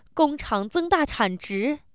fear